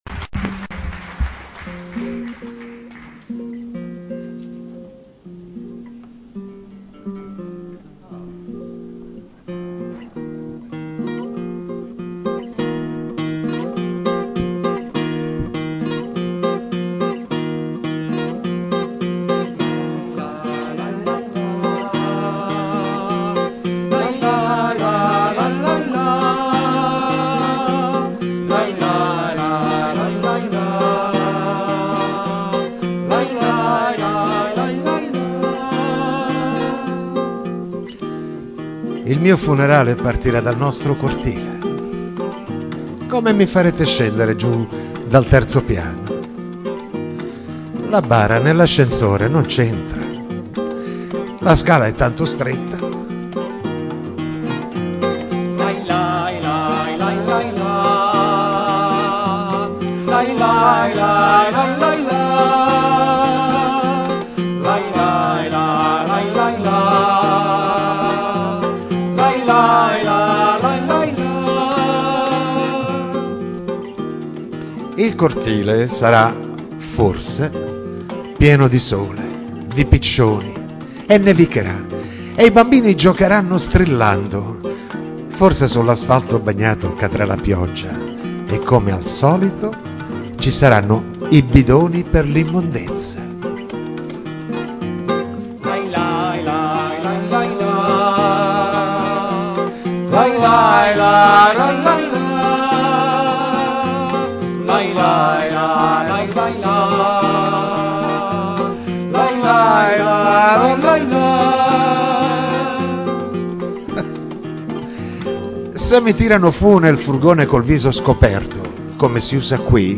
Nessun sottofondo improvvisato, bensì  musica composta nota per nota, dettata dalla poesia stessa in alcune occasioni; in altre, musica di grandi maestri, scelta per le analogie del contenuto del testo e della partitura.
live